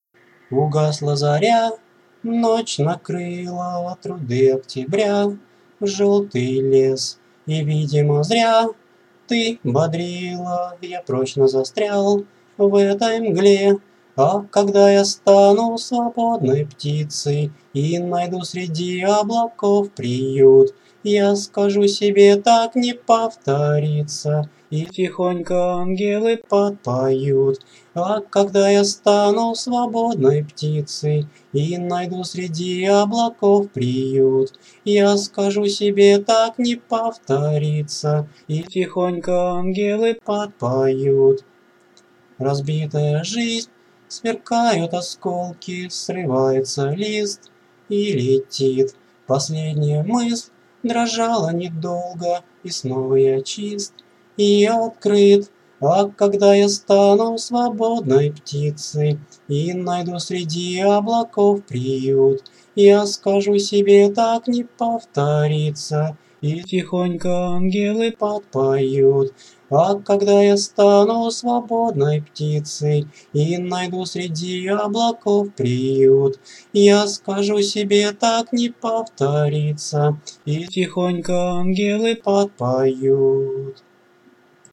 Песня
Прослушать в авторском исполнении (только вокал):